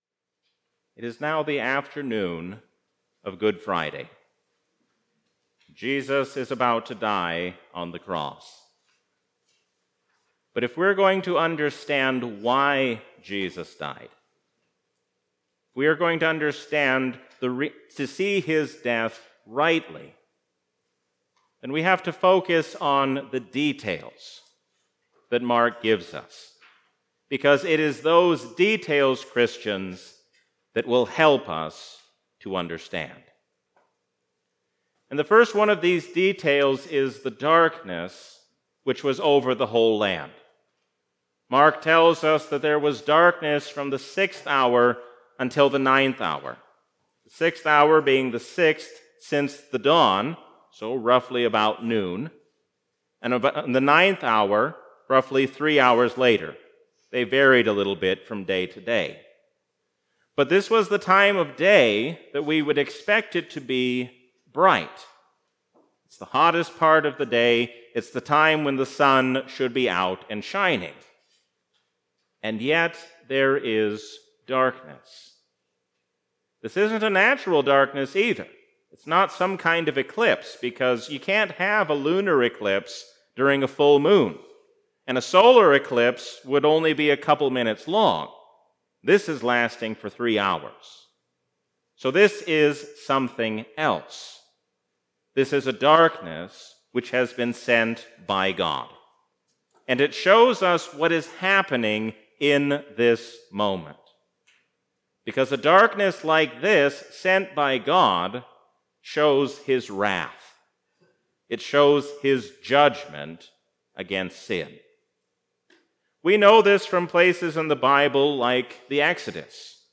A sermon from the season "Trinity 2024." Seek true peace and unity with one another by turning to God.